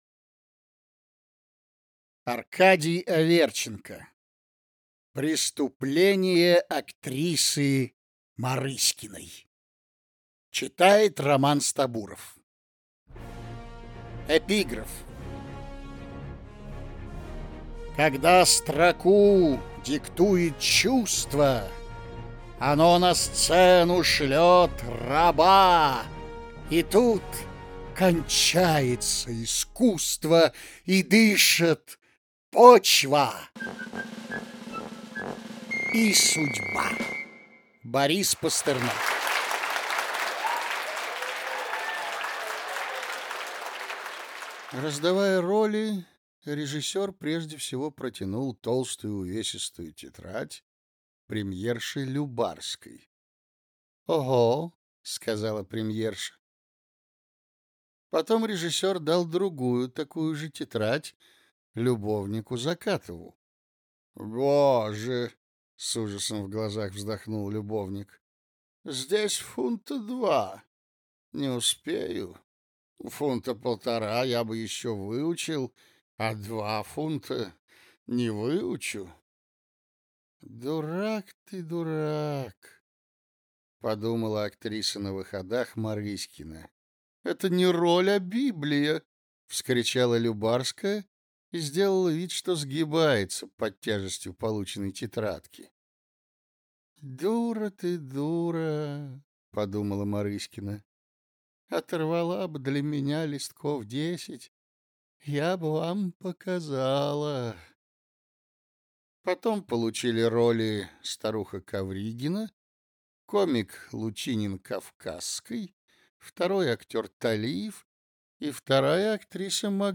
Рассказы Аркадия Аверченко.